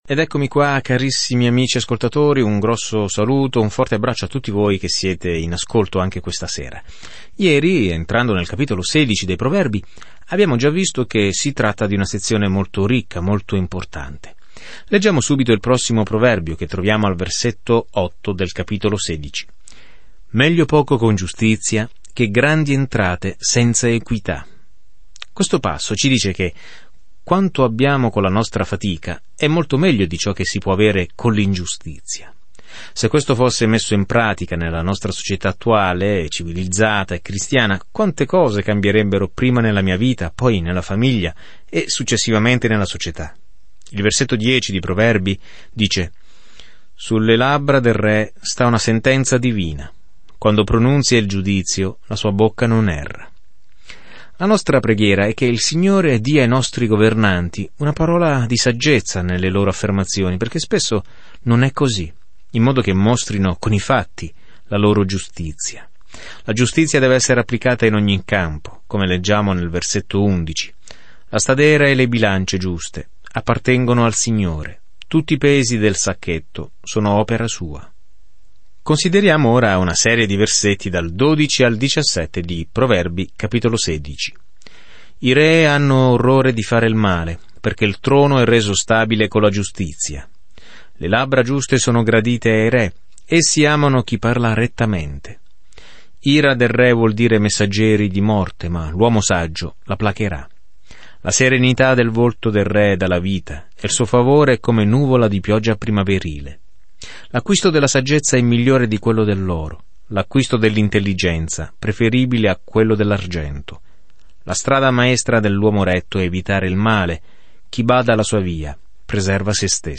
Scrittura Proverbi 16:8-33 Proverbi 17:1-3 Giorno 18 Inizia questo Piano Giorno 20 Riguardo questo Piano I proverbi sono brevi frasi tratte da lunghe esperienze che insegnano la verità in un modo facile da ricordare: verità che ci aiutano a prendere decisioni sagge. Viaggia ogni giorno attraverso i Proverbi mentre ascolti lo studio audio e leggi versetti selezionati della parola di Dio.